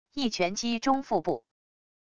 一拳击中腹部wav音频